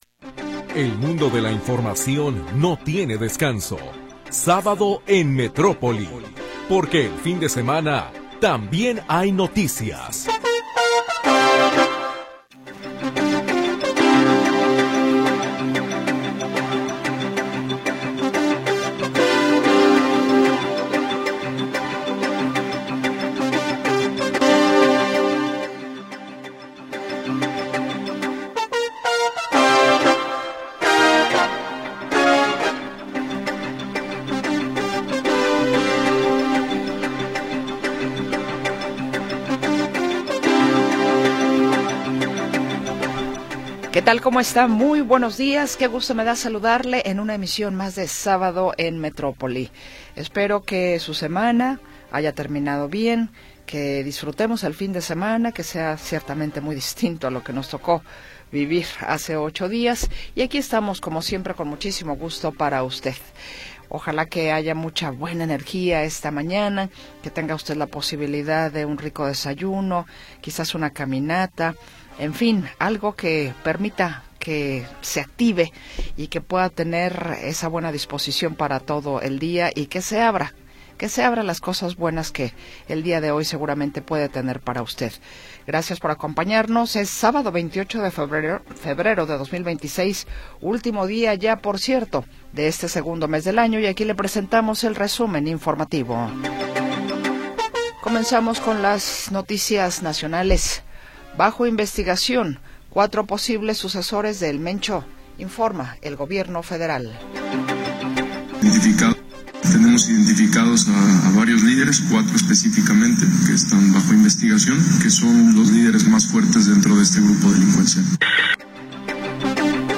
Primera hora del programa transmitido el 28 de Febrero de 2026.